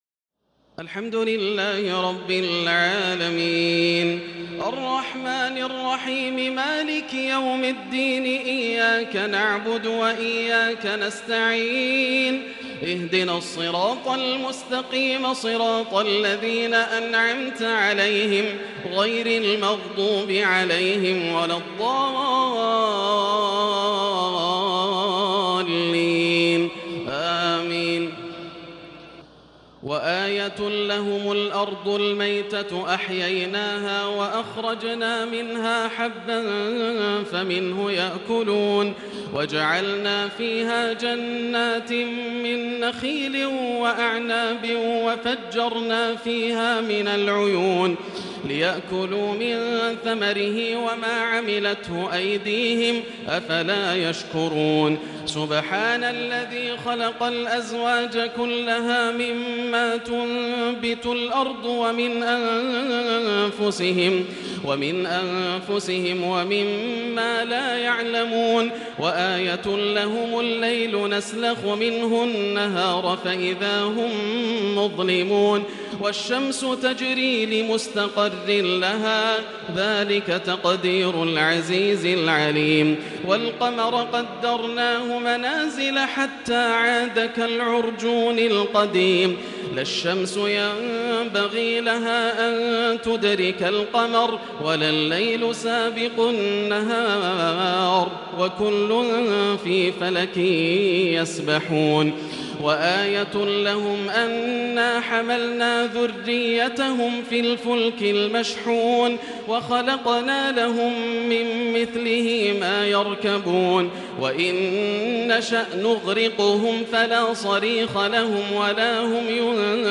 تراويح ليلة 22 رمضان 1440هـ من سور يس(33-83) والصافات(1-138) Taraweeh 22 st night Ramadan 1440H from Surah Yaseen and As-Saaffaat > تراويح الحرم المكي عام 1440 🕋 > التراويح - تلاوات الحرمين